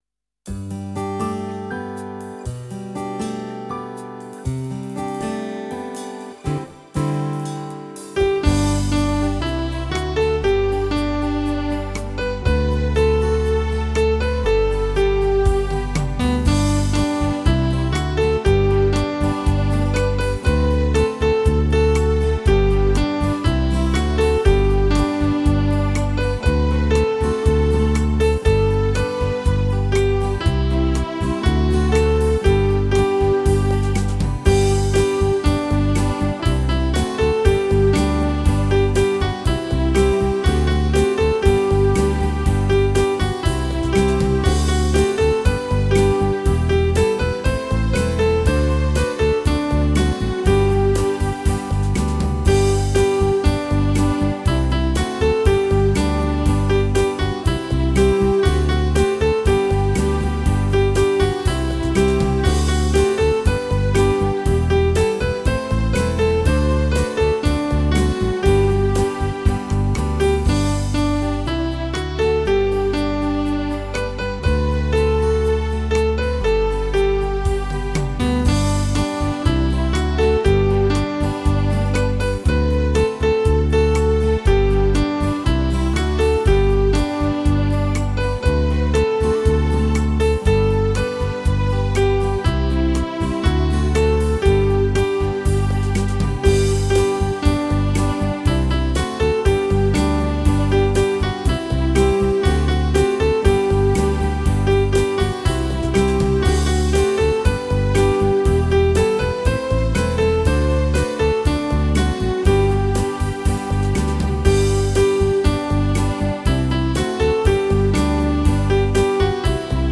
Hymn szkoły